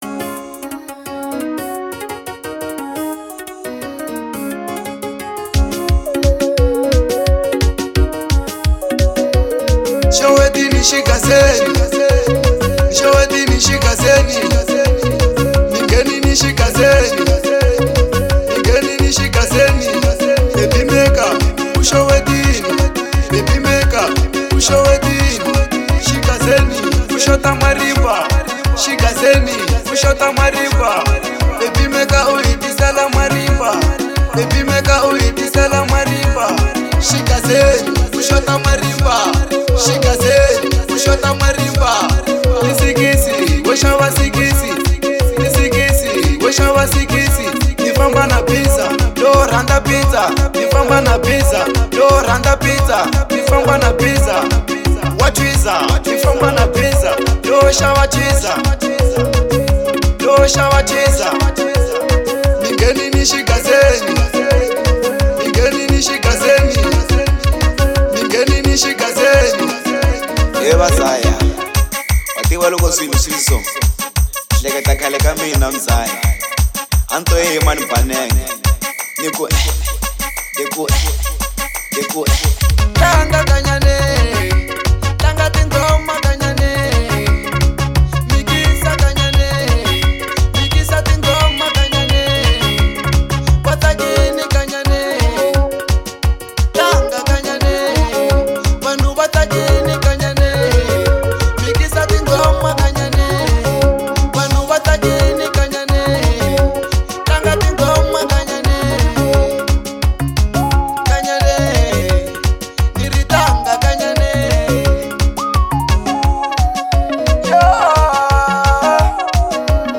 04:46 Genre : Xitsonga Size